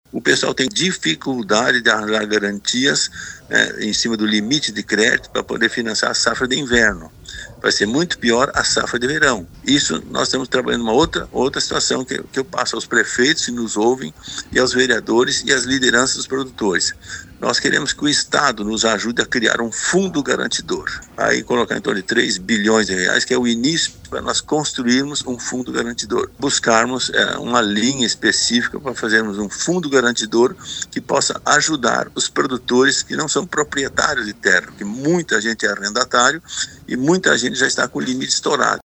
Luis Carlos Heinze ainda destaca que trabalha na definição de uma parceria com o governo gaúcho para criar um fundo garantir com, pelo menos, 3 bilhões de reais a fim de ajudar os agricultores que têm dificuldade para financiar a atual safra de inverno, especialmente arrendatários de terra.